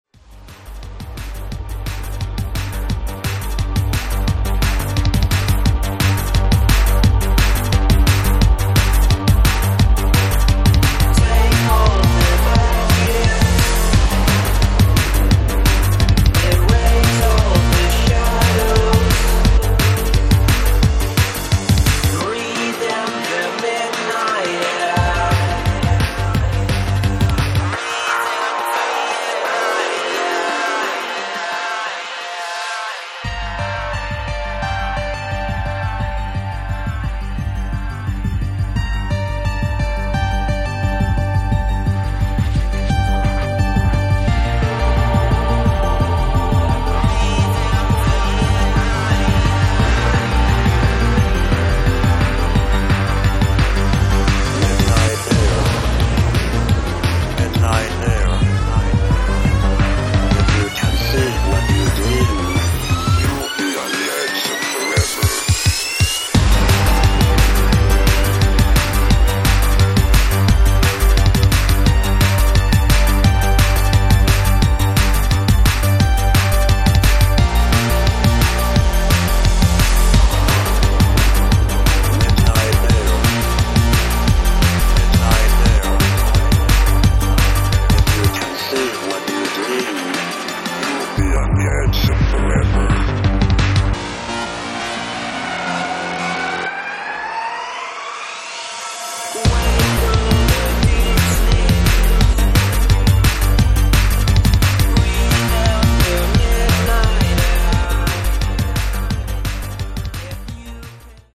to beautiful Epic Trance-inspired full vocal tracks